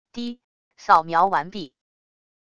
嘀……扫描完毕wav音频